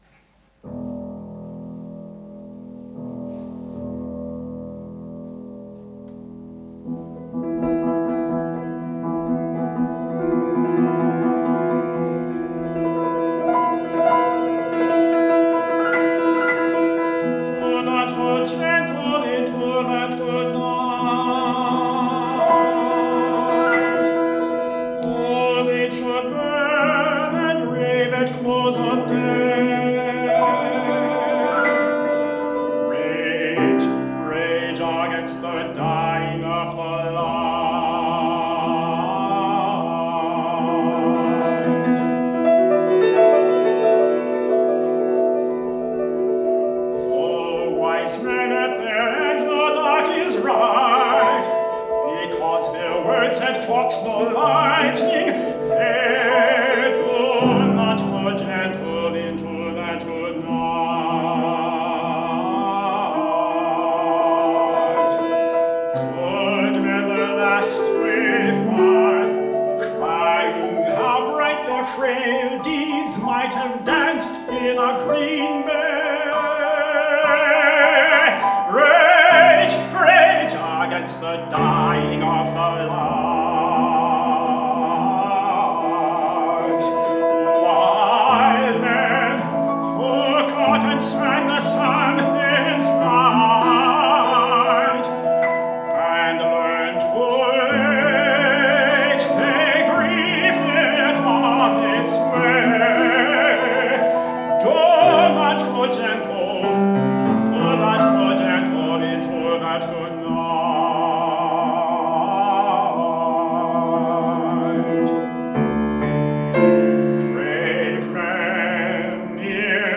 for Tenor and Piano (2011)